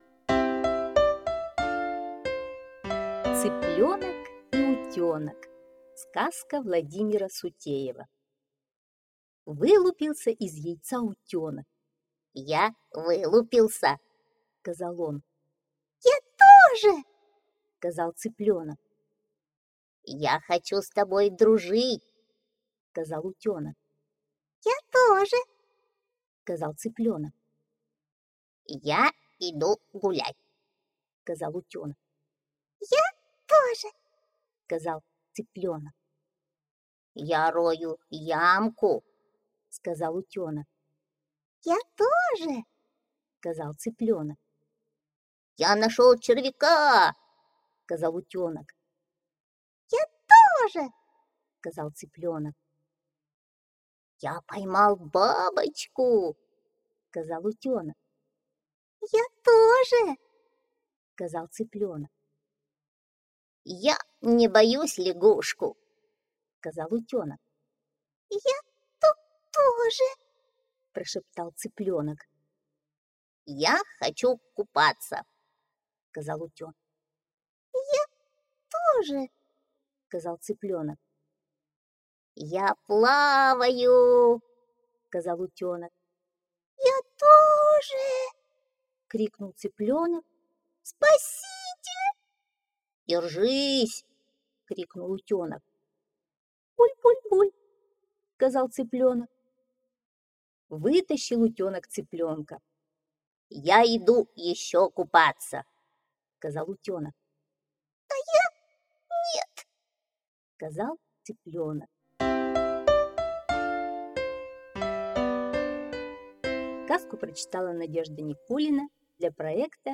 Аудиосказка «Цыплёнок и утёнок»